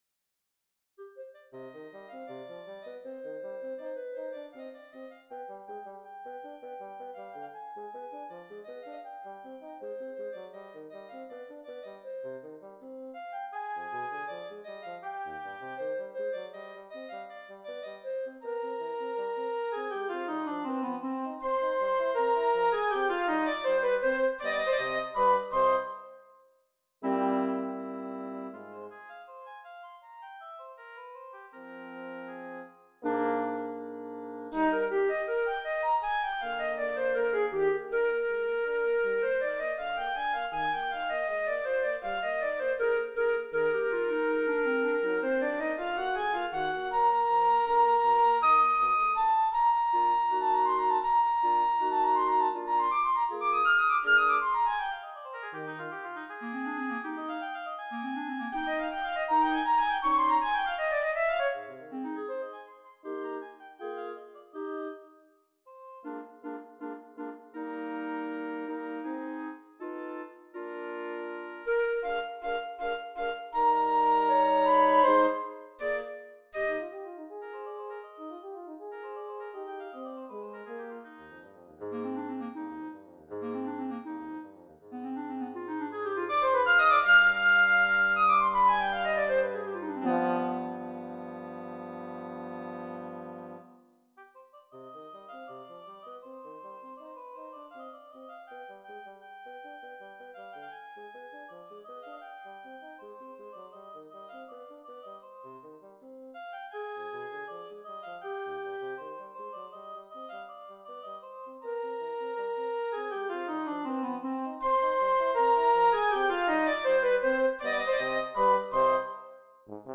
Voicing: Woodwind Quintet